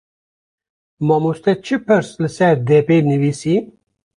Uitgesproken als (IPA)
/pɪɾs/